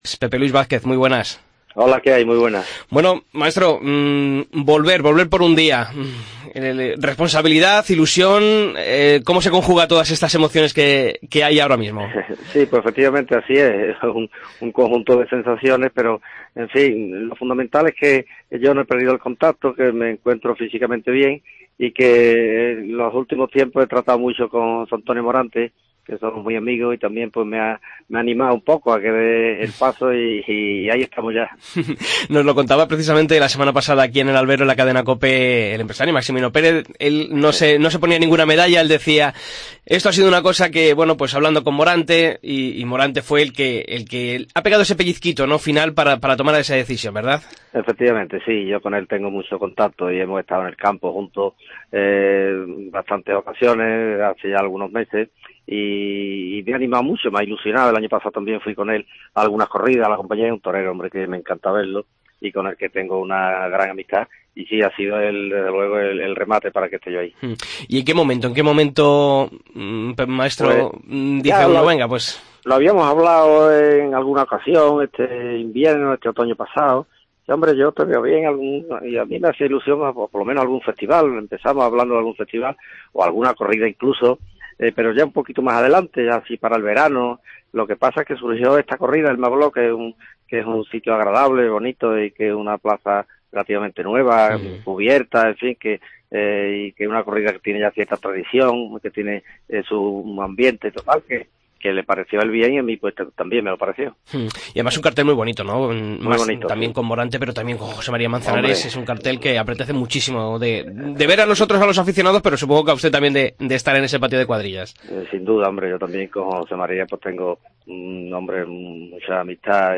Escucha la entrevista a Pepe Luis Vázquez en El Albero